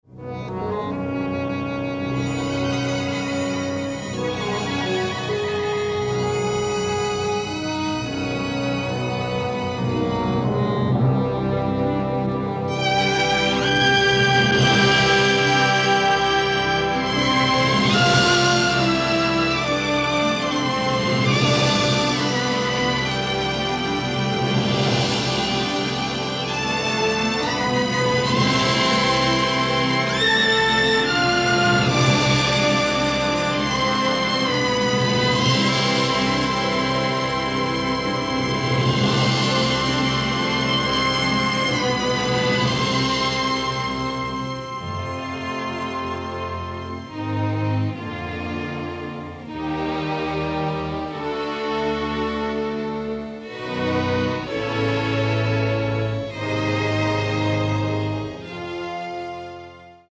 rousing orchestral work